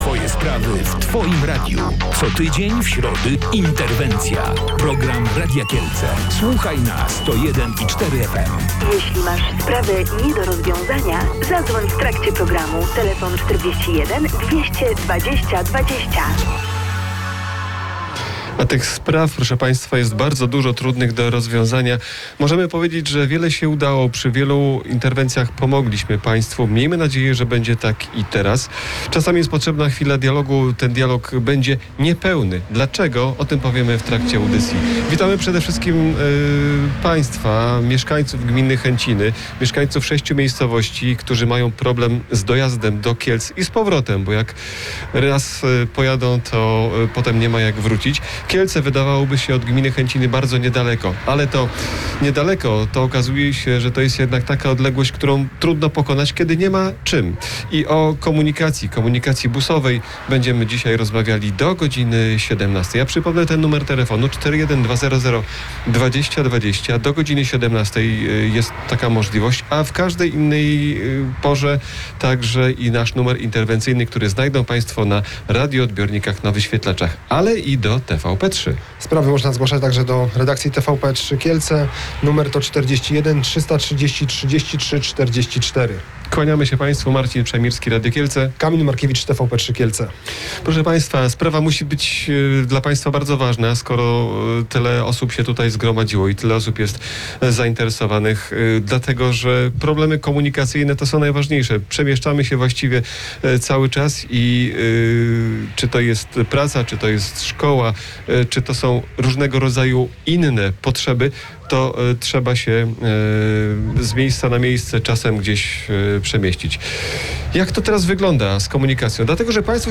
O tym jak rozwiązać problemy komunikacyjne rozmawialiśmy w programie Interwencja.